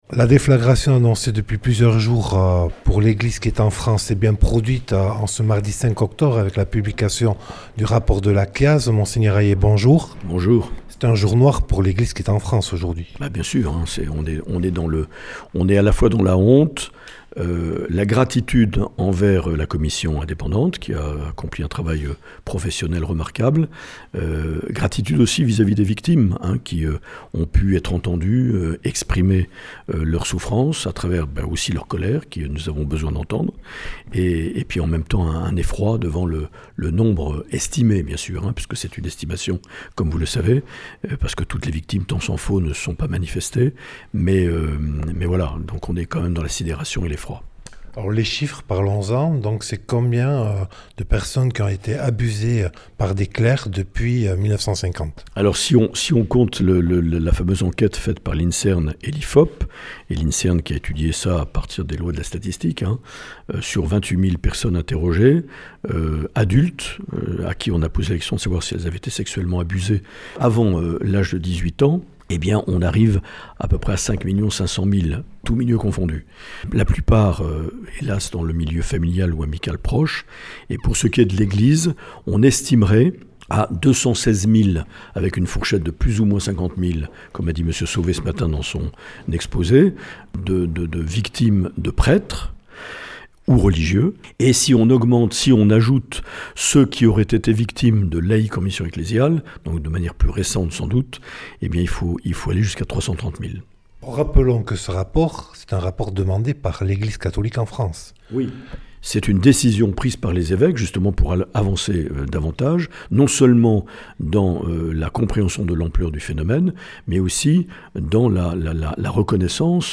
Mgr Marc Aillet a répondu aux questions de Radio Lapurdi à l’issue de la conférence de presse qui s’est tenue à l’évêché de Bayonne le mardi 5 octobre 2021 concernant la publication du rapport de Commission indépendante sur les abus sexuels dans l’Église (CIASE) présidée par Jean-Marc Sauvé.